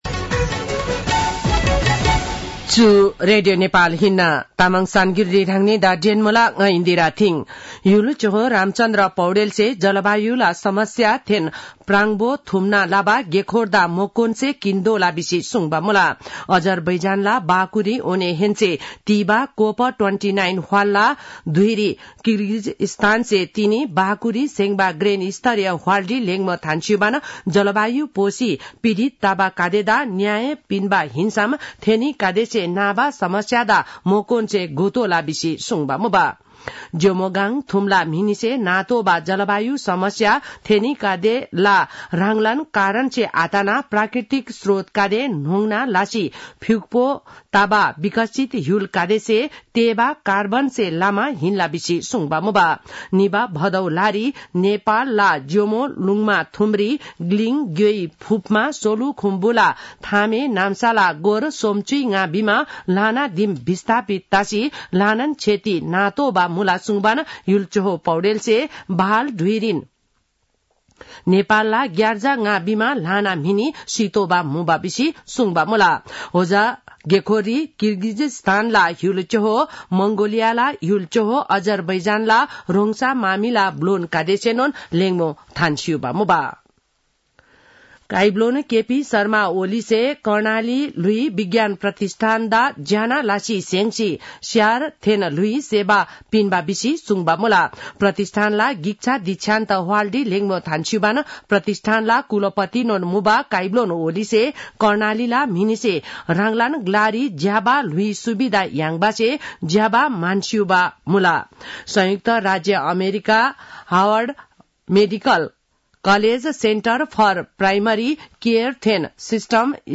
An online outlet of Nepal's national radio broadcaster
तामाङ भाषाको समाचार : २९ कार्तिक , २०८१
Tamang-news-7-28.mp3